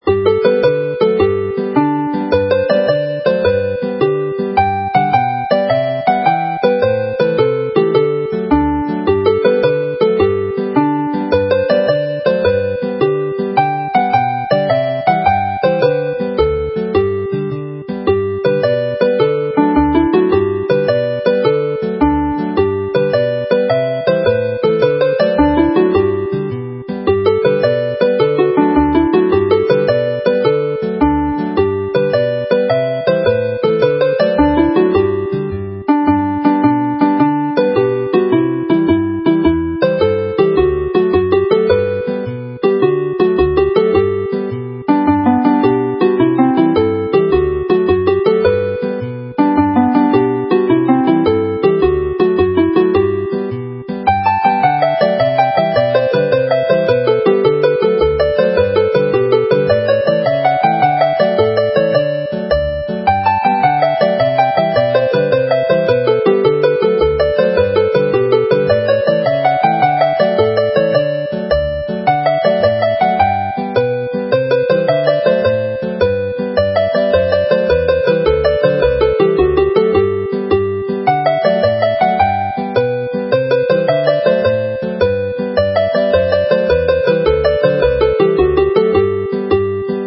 set jigiau llawen
This set of jolly jigs